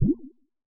Deep Cute Notification.wav